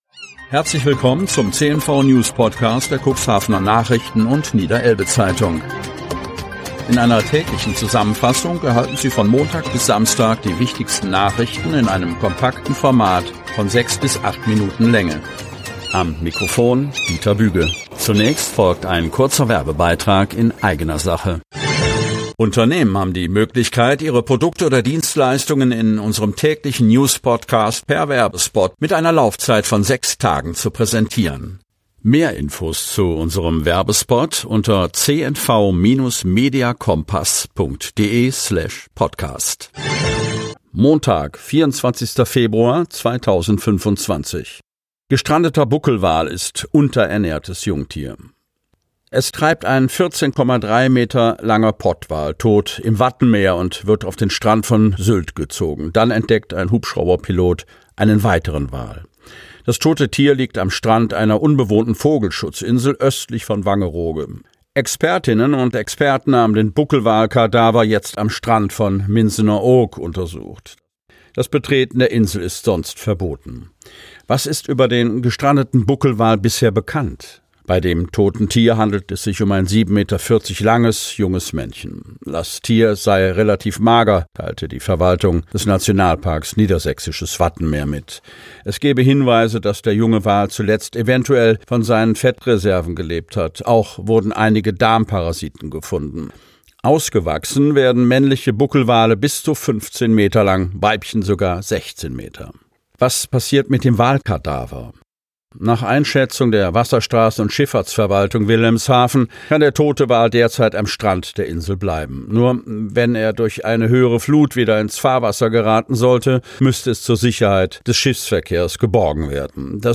Ausgewählte News der Cuxhavener Nachrichten und Niederelbe-Zeitung am Vorabend zum Hören!